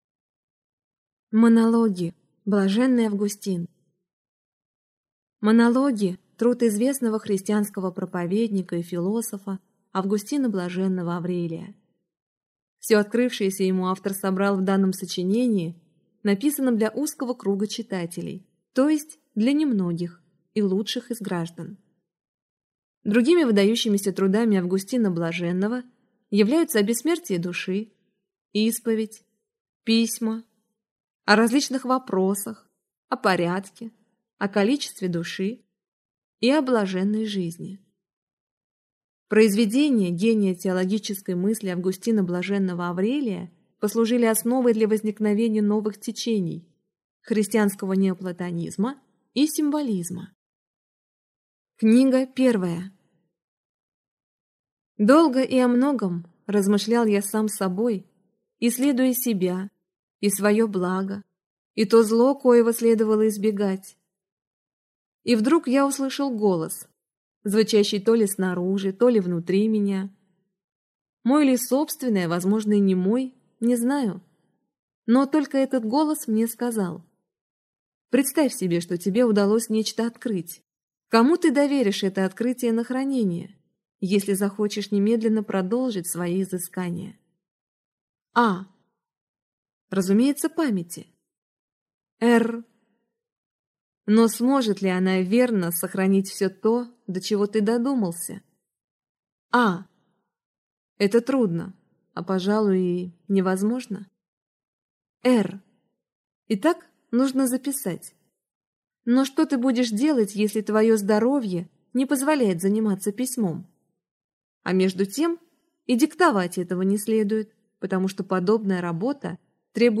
Аудиокнига Монологи | Библиотека аудиокниг